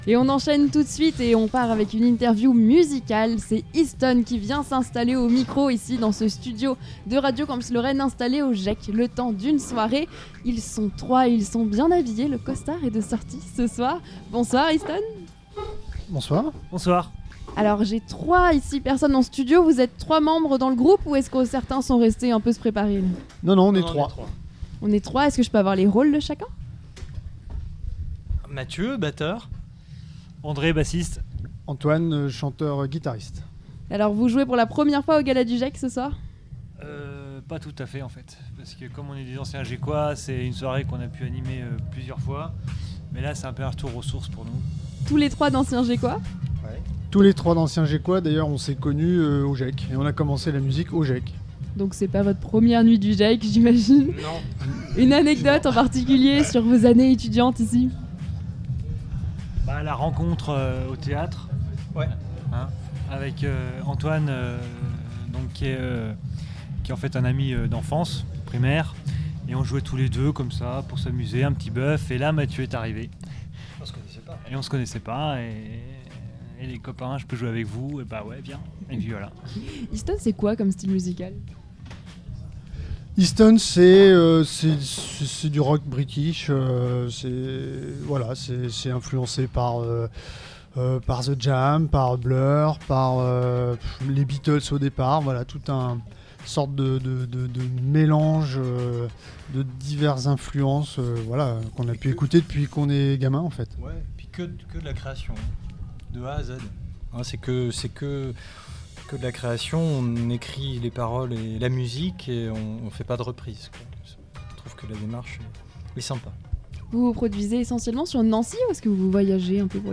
Et alors que les portes s’ouvrent et que les participants commencent à affluer, les groupes se succèdent dans le studio de Radio Campus Lorraine. Ils sont maintenant trois à s’installer, et les costumes de soirée sont de sortie.
gec_itw_eastone.mp3